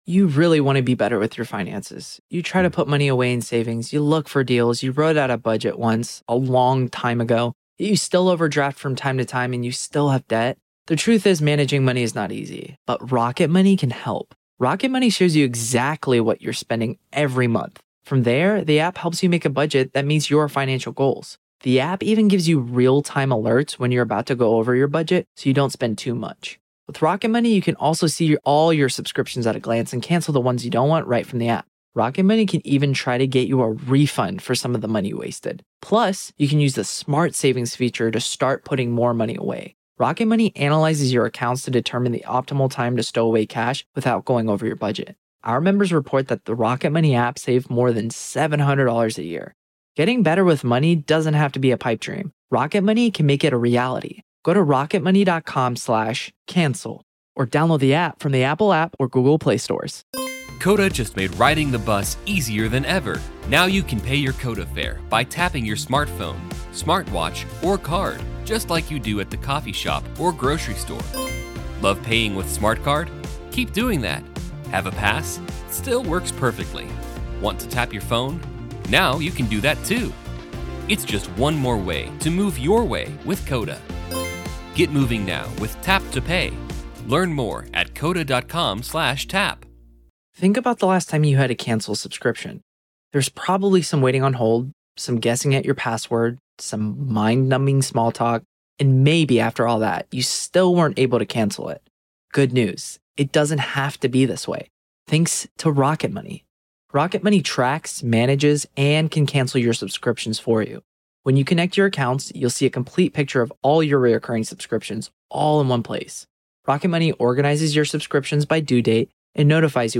Our exclusive coverage of the Alex Murdaugh murder trial is heating up. Don't miss a beat of the gripping testimony and explosive evidence as the accused faces life-altering charges for the brutal murder of his own family.